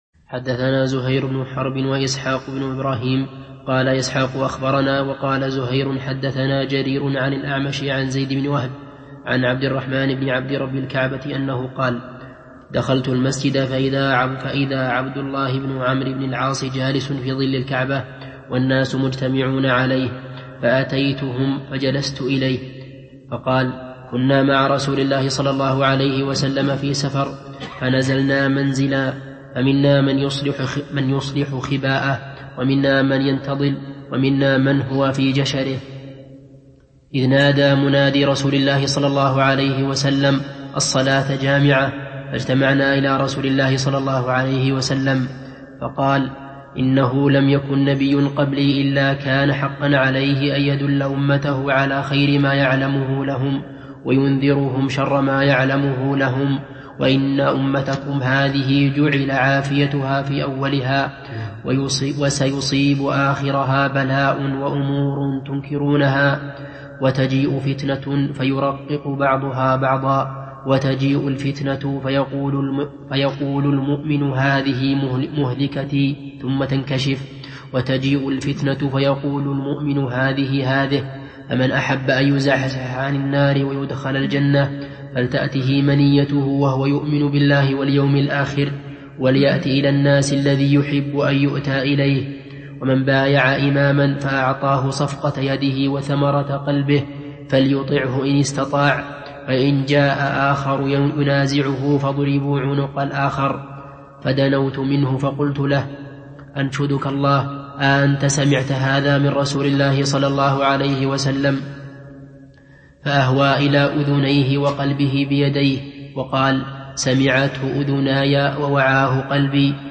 Download audio file Downloaded: 564 Played: 582 Artist: الشيخ ابن عثيمين Title: شرح حديث: من بايع إماماً فأعطاه صفقة يده Album: موقع النهج الواضح Length: 16:40 minutes (3.89 MB) Format: MP3 Mono 22kHz 32Kbps (VBR)